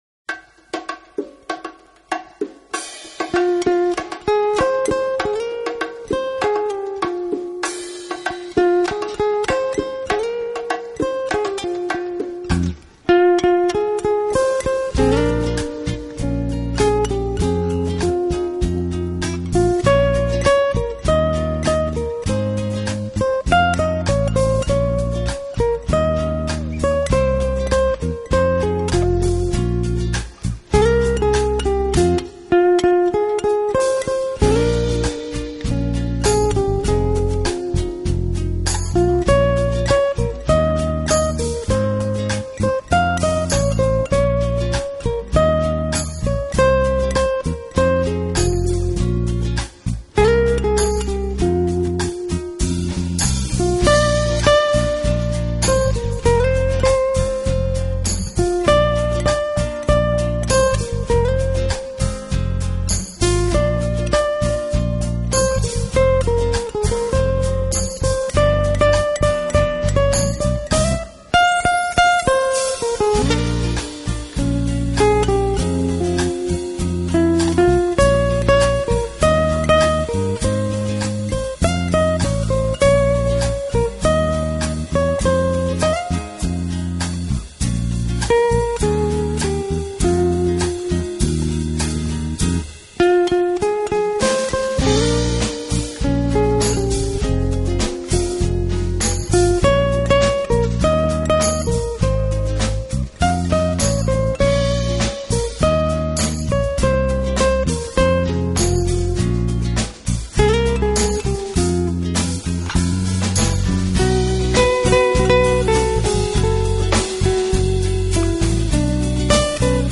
音乐类型：Latin
低频浑厚圆润，气韵生动且充满轻松悠闲的感受，浪漫的气氛洋溢在整个空
浪漫如诗的吉他演奏 ，清澈饱满的录音效果，最佳的后制过程，最顶级的压
击和贝斯加上浪漫的伦巴节奏，在这个炎热的夏天，给人们带来一点点的清凉